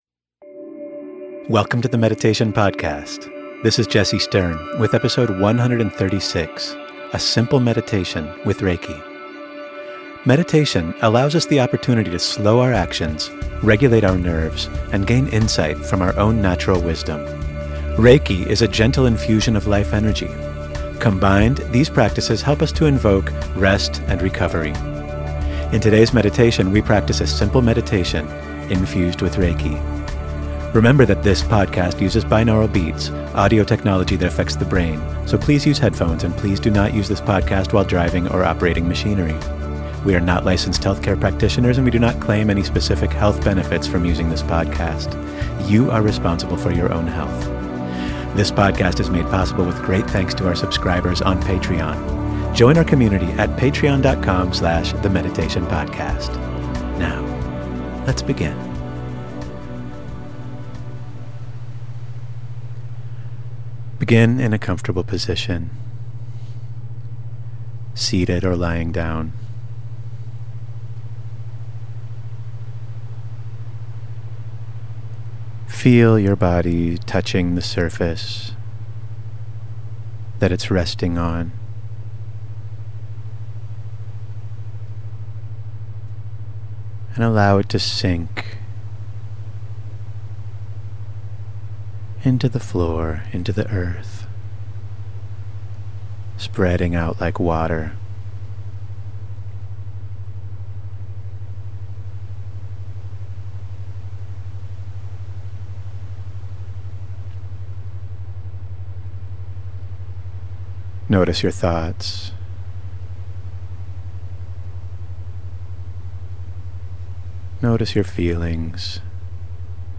tmp136-simple-meditation-with-reiki.mp3